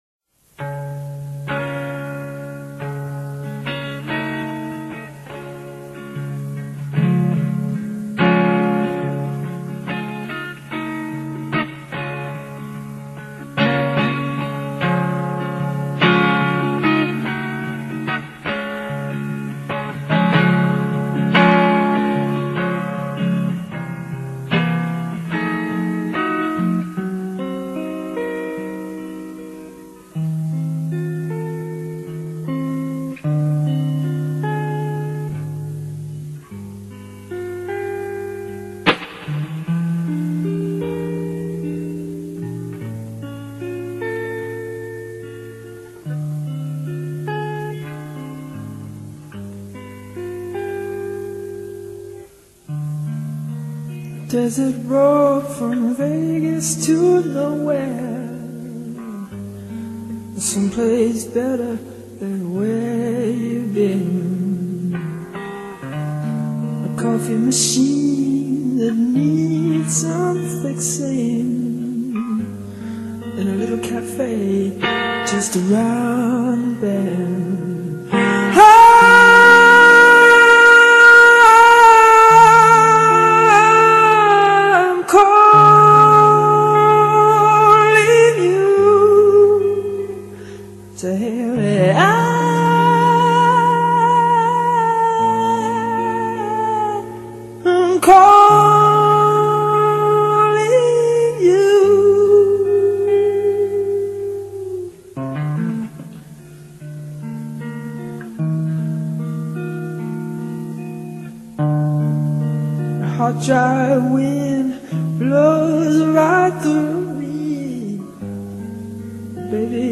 Instrumentais Para Ouvir: Clik na Musica.